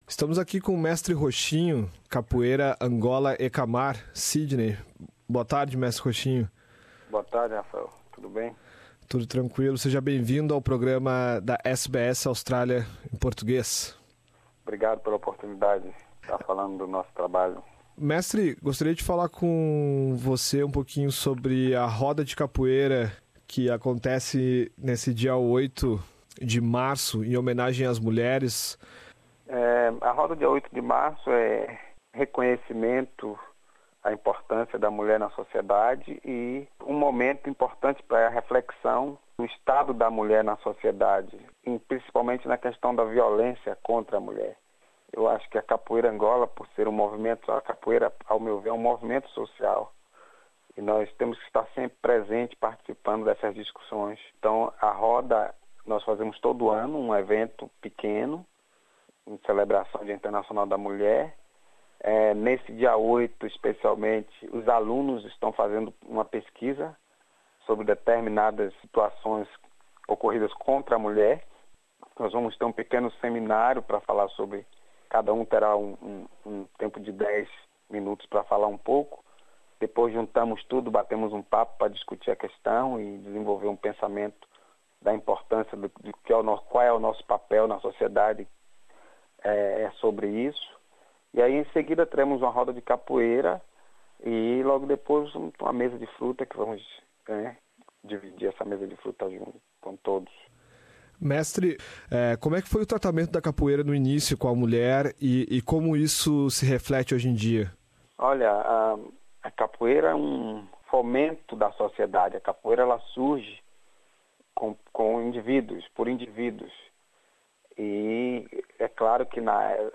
Nesta entrevista